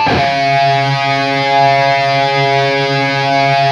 LEAD C#2 LP.wav